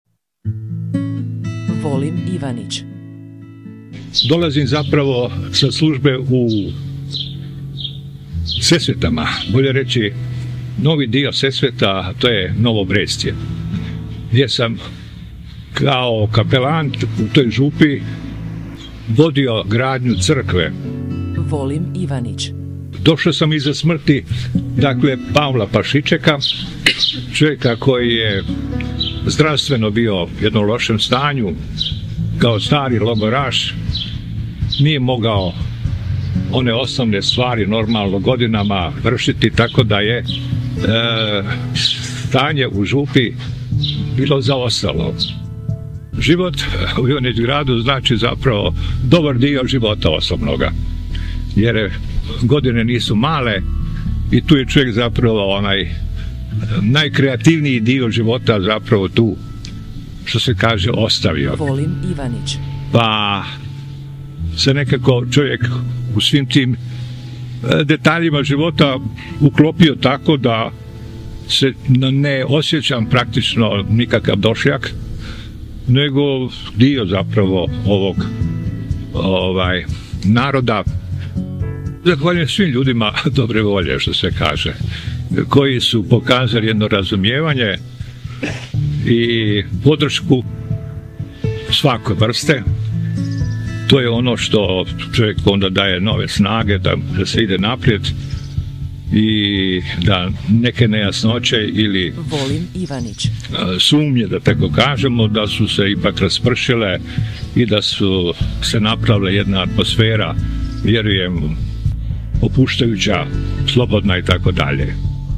Originalni audio zapis razgovora